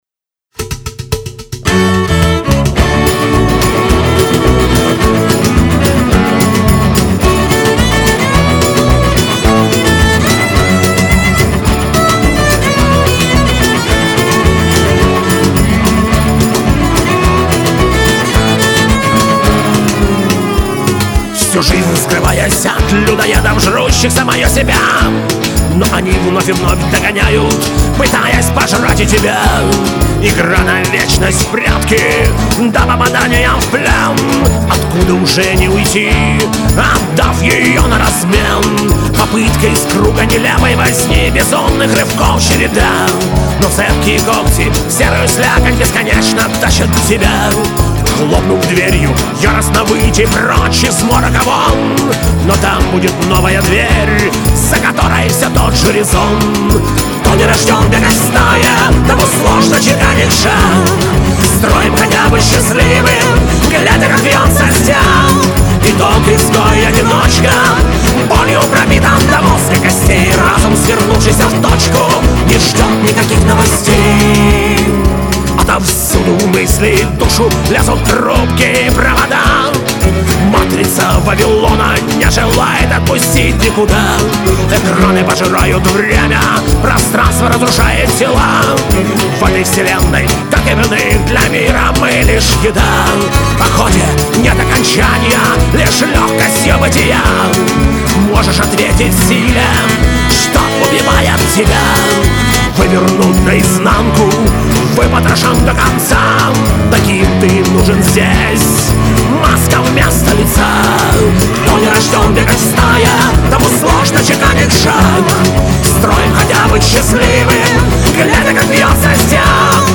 Жанр: фолк-панк
вокал, гитары
бэк, перкуссия, скиффл-инструменты.
ритм-гитара.
бас.
джа-кахон.
виолончель.